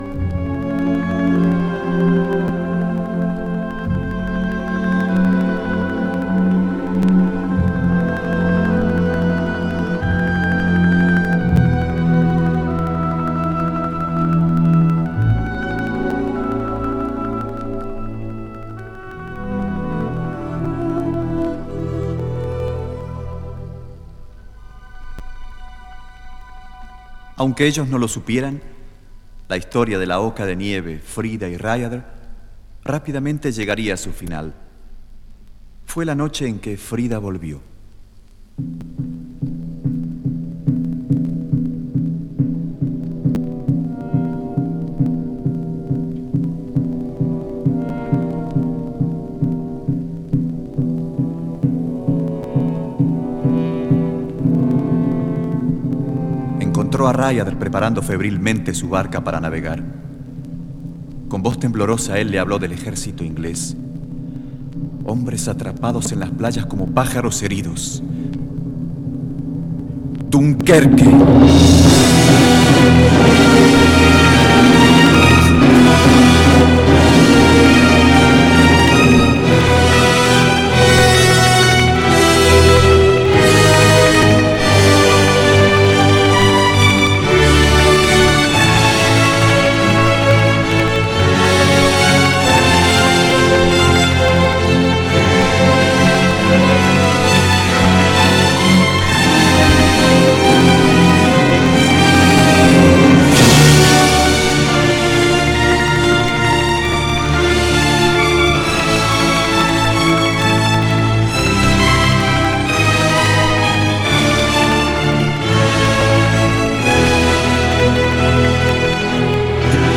Poema Sinfónico.
Actor y Cantante.